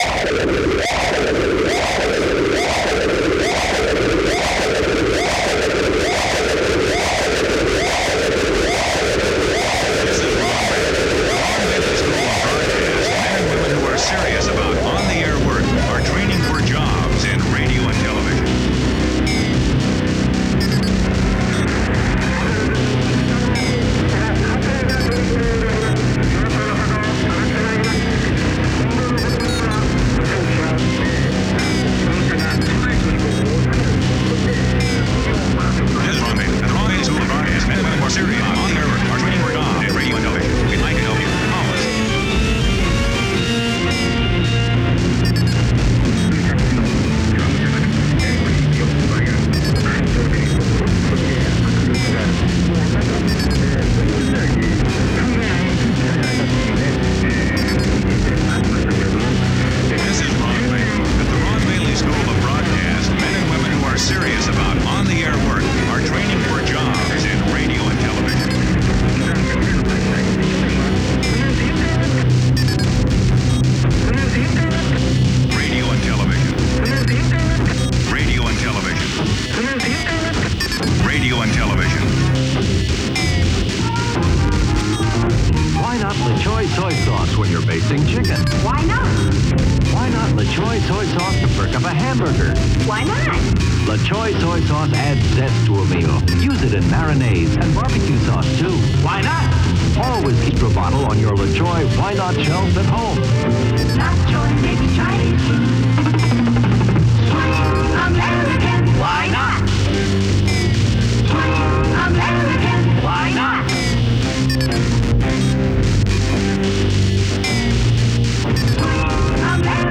Synthesizers
Recorded: Cyclops' Lair - Middleburg Heights, Ohio - 2022